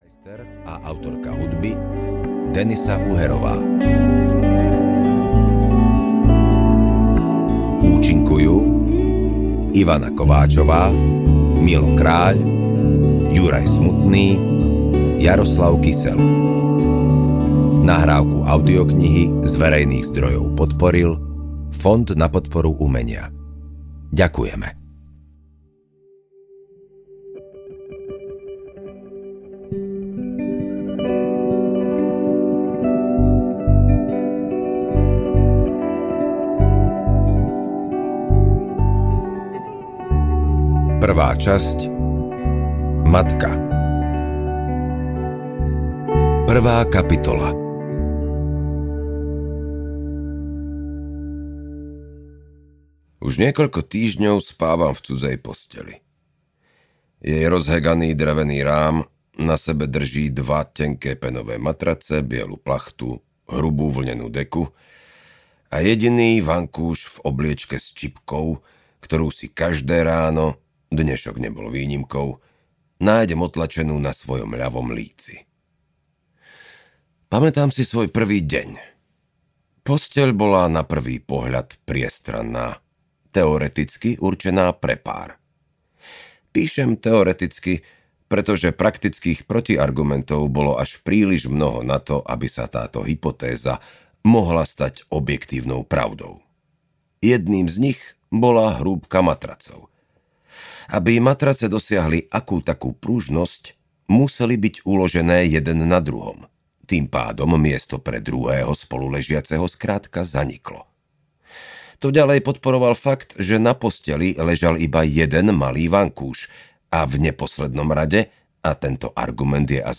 Príbeh nekonečných koncov audiokniha
Ukázka z knihy